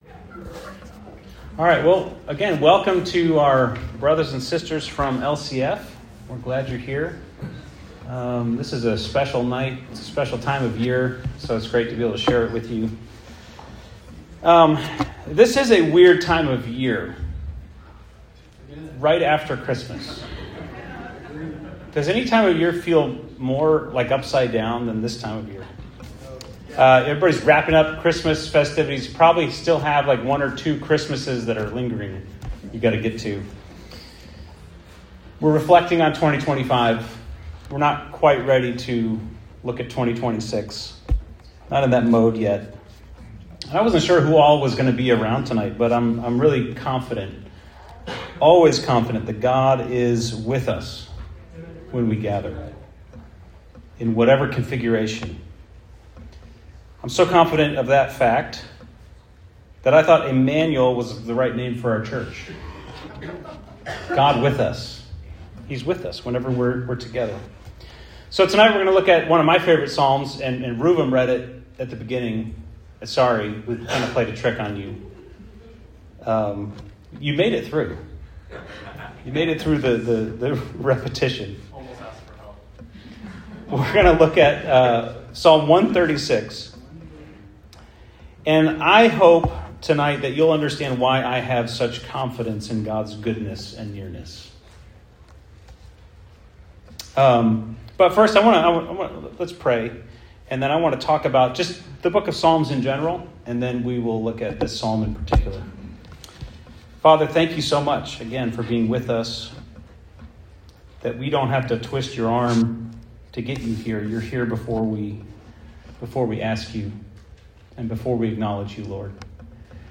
Weekly sermon audio from Emmanuel Christian Fellowship in Lexington, KY.